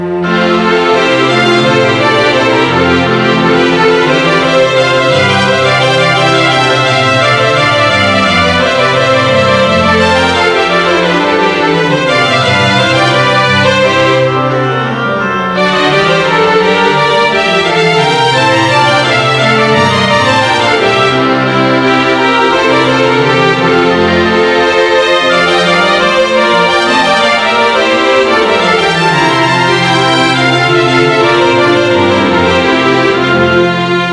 Kategorien: Klassische